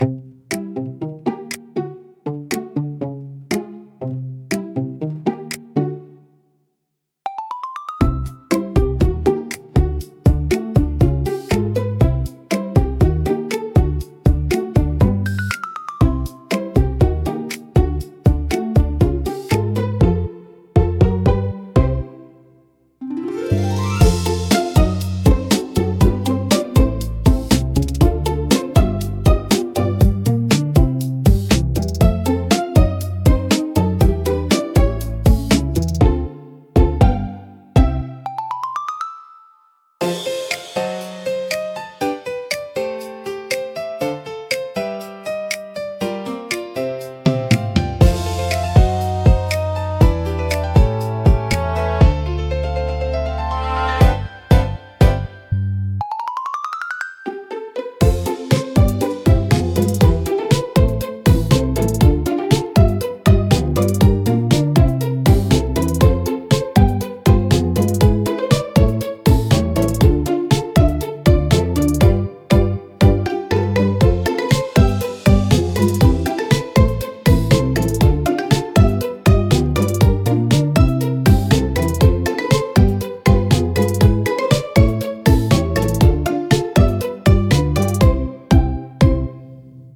聴く人に安心感と温かさを届け、自然で可愛らしい空間を演出します。